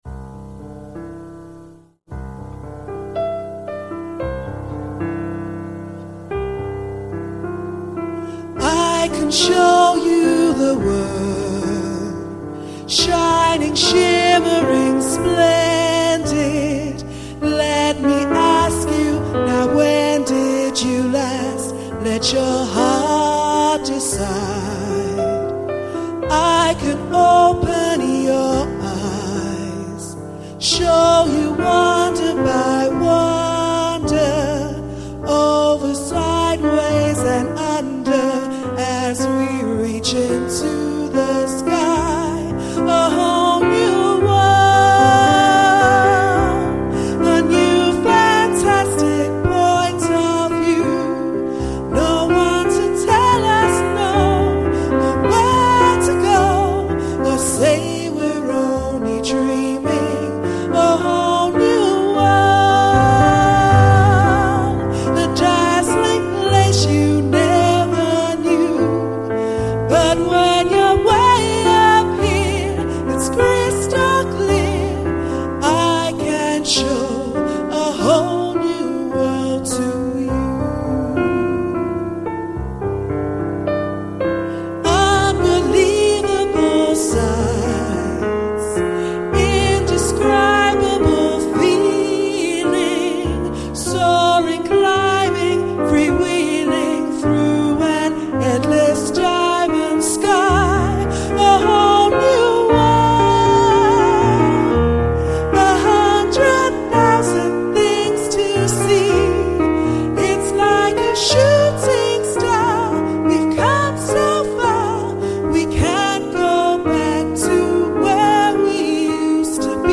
St Andrews Players - Live!